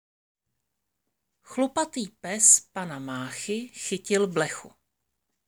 Tady si můžete poslechnout audio na výslovnost CH.